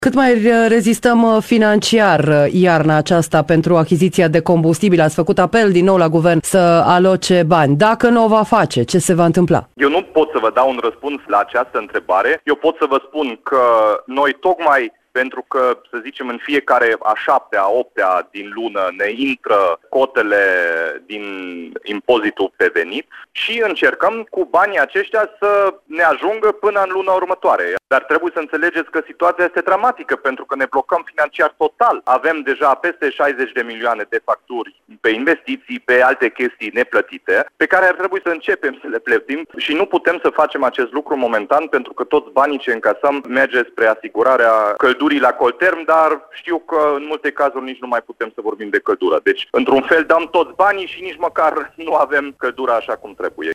Întrebat, la Radio Timișoara, până când mai ajung banii Dominic Fritz a răspuns că speră să fie asigurată finanțarea până pe 7 februarie, când vor intra în conturi cotele din impozitul pe venit.